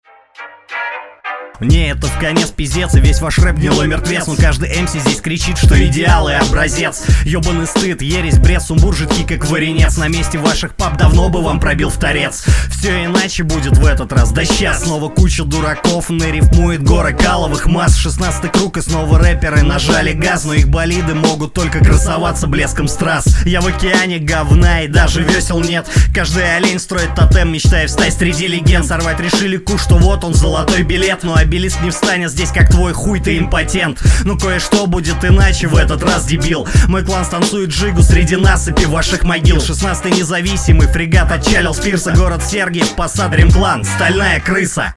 не можешь уложить слово "16" в ритмику
Звучит уверенно и саркастично, но не стоит так углубляться в копро-темы и выдавать незавуалированные банальные оскорбления, можно выражаться закрученнее и интереснее